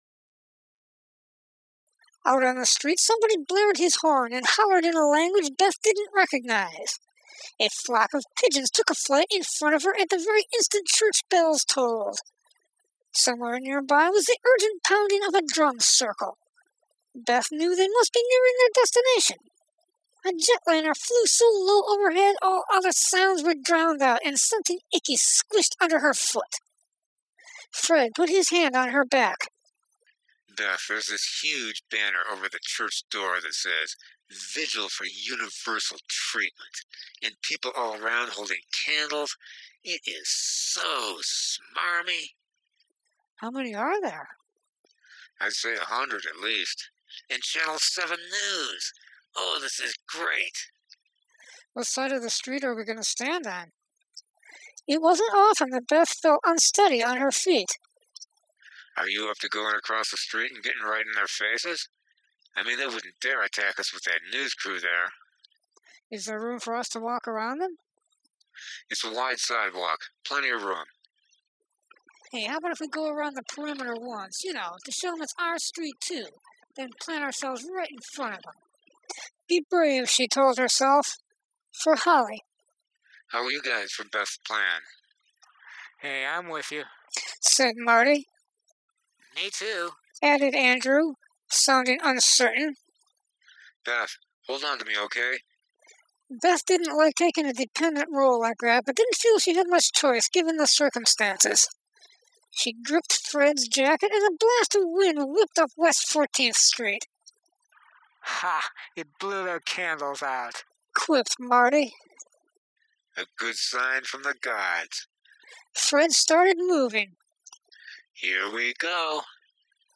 (Please note: The author choose a young woman's voice for the third-party narrator because they were still using a female pen name.)
PLEASE NOTE: YOU WILL NEED A STEREO HEADSET OR SPEAKERS.